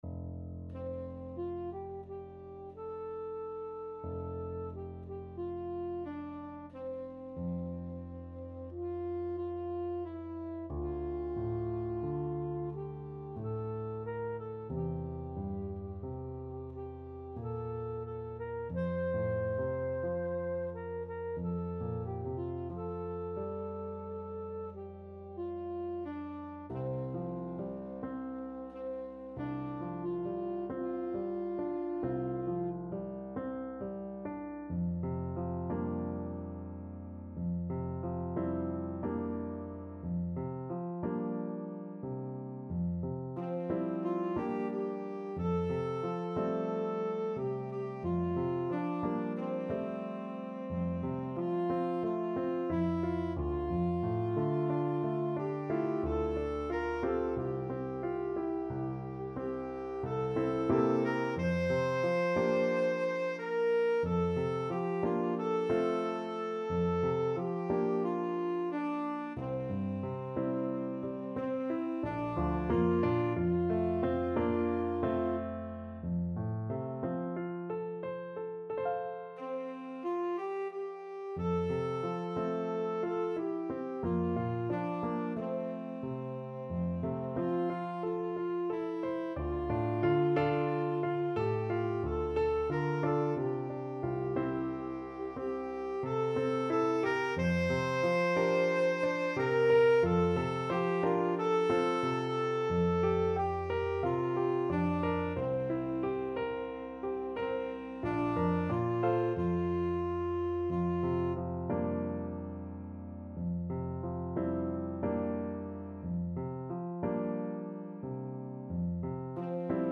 4/4 (View more 4/4 Music)
Andante cantabile = c. 90